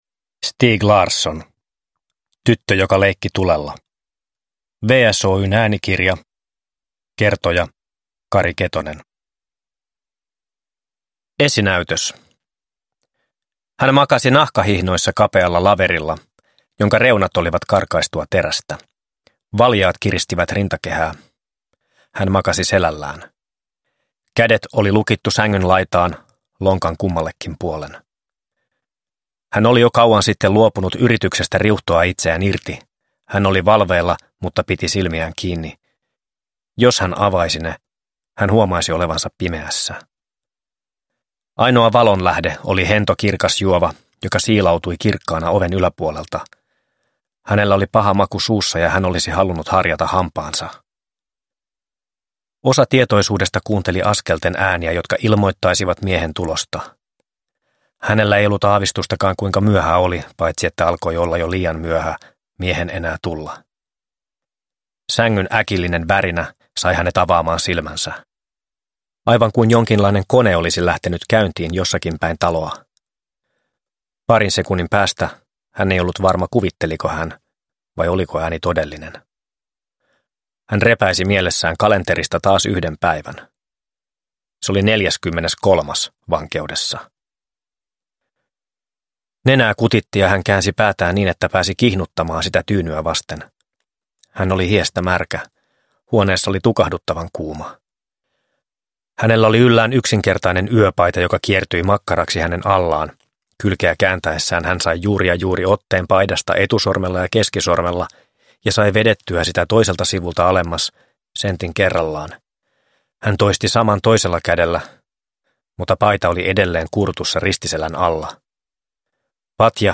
Tyttö joka leikki tulella (ljudbok) av Stieg Larsson